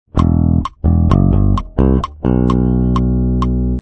La linea es sencilla pero con toques de contratiempo de corchea, lo que hace, que sea una linea de bajo muy divertida de tocar.
🔊Feel Good Inc Bass Tab 1👇🏻
En los primeros dos compases se puede apreciar la pregunta del riff , decimos esto porque este es un groove de bajo con una clara pregunta y respuesta.
Feel-Good-Inc-riff-1.mp3